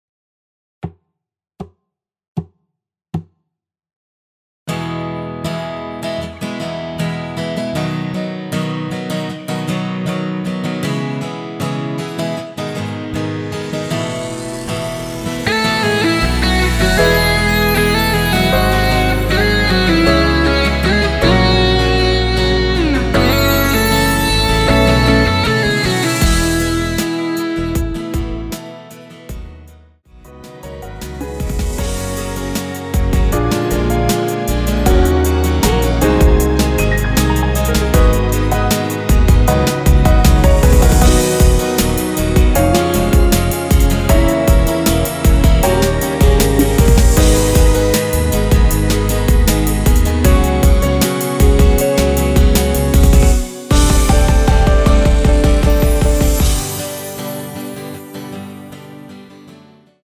전주 없이 시작하는 곡이라서 노래하기 편하게 카운트 4박 넣었습니다.(미리듣기 확인)
앞부분30초, 뒷부분30초씩 편집해서 올려 드리고 있습니다.
중간에 음이 끈어지고 다시 나오는 이유는